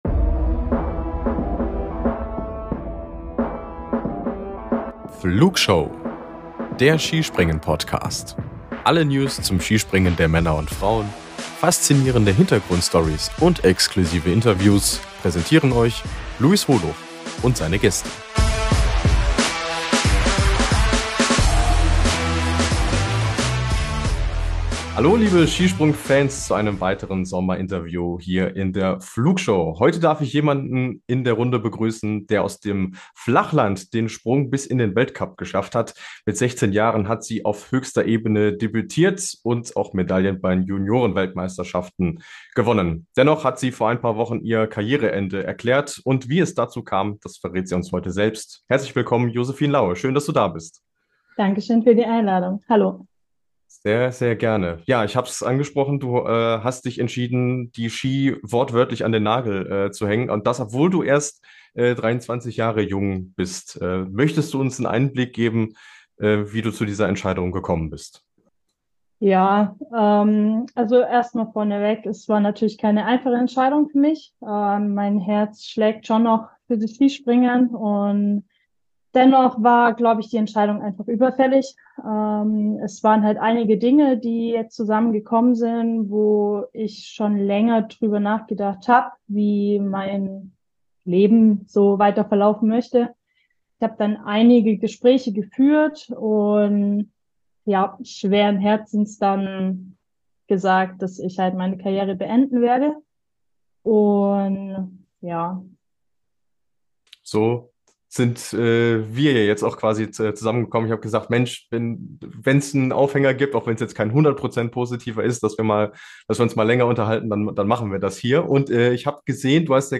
Interview ~ Wintersport Podcast